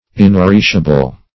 Inappreciable \In`ap*pre"ci*a*ble\, a. [Pref. in- not +